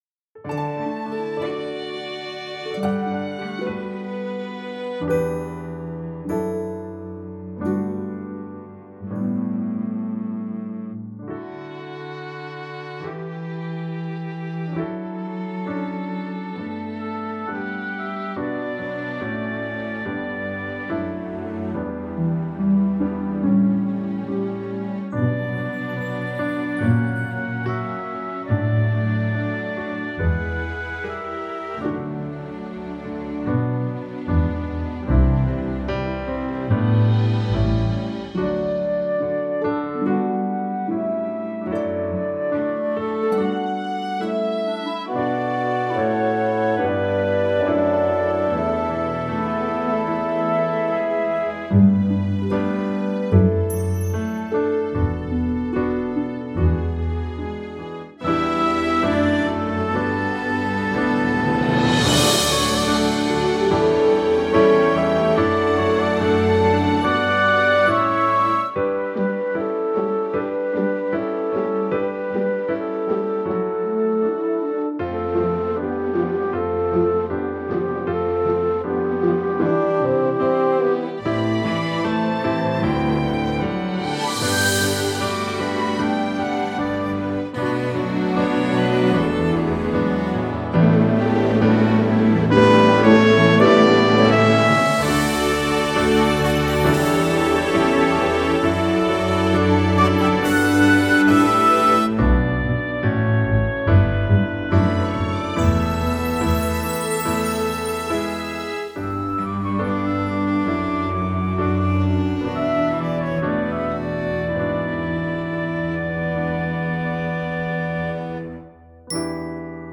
Proud Of Your Boy Custom Backing Track | Ipswich Hospital Community Choir
Genre: Musical.